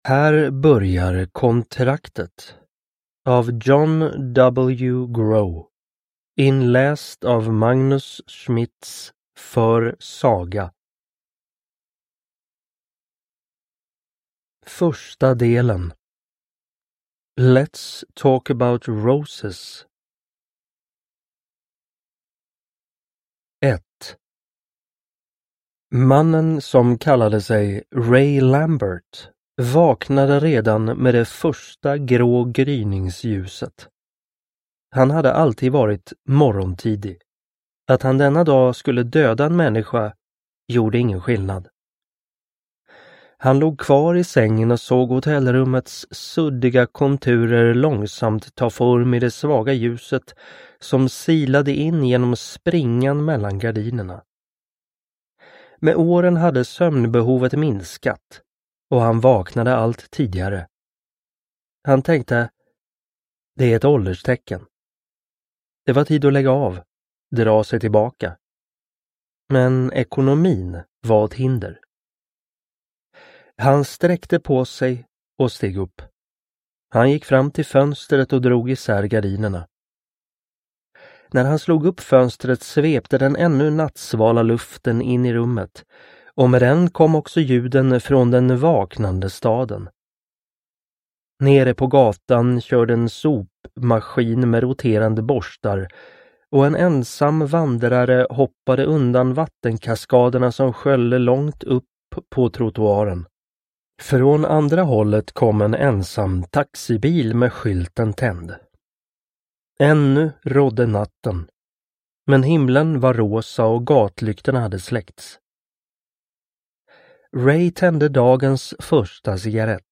Kontraktet / Ljudbok